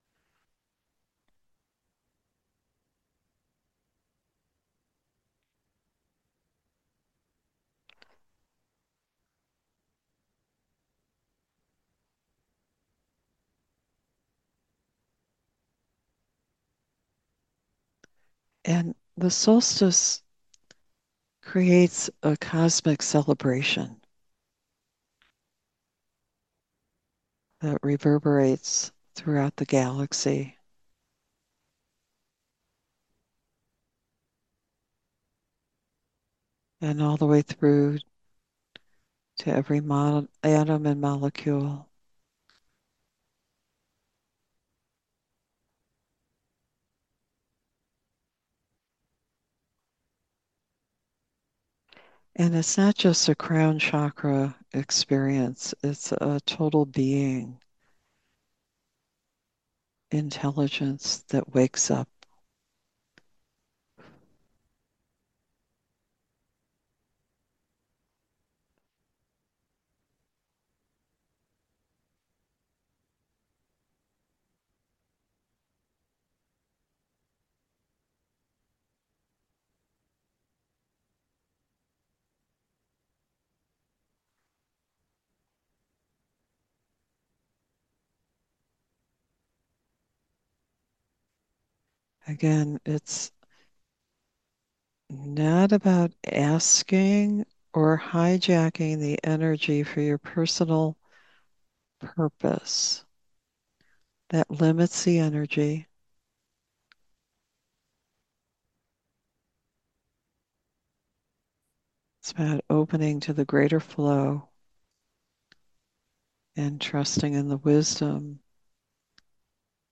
Genre: Guided Meditation.